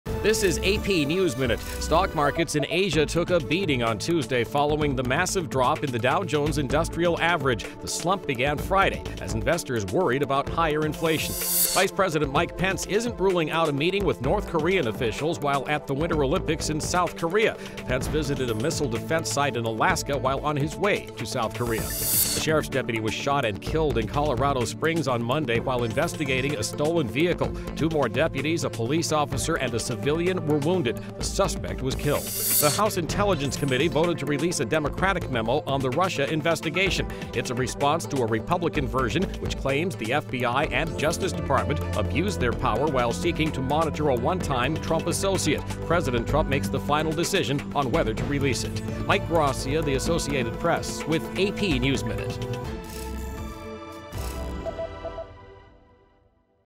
美语听力练习素材:受美国股市影响亚洲股市暴跌